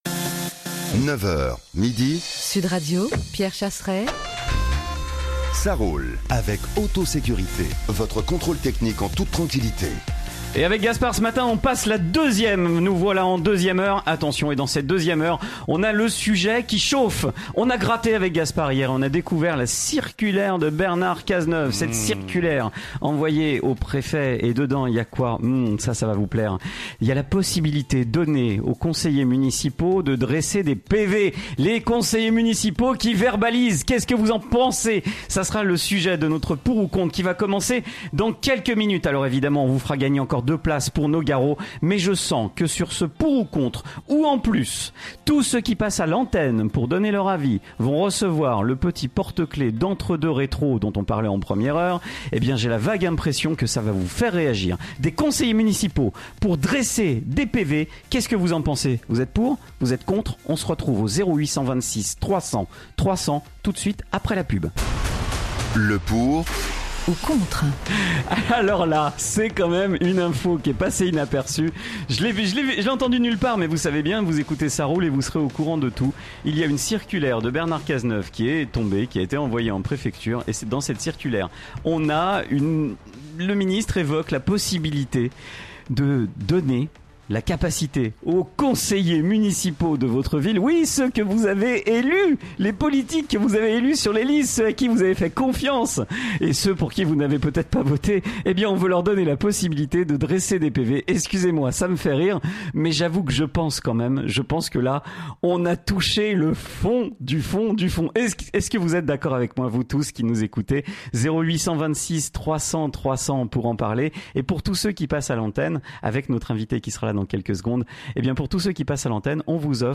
était l’invité du débat « Pour ou contre » qui avait pour sujet la dernière circulaire de Bernard Cazeneuve.